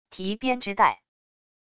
提编织袋wav音频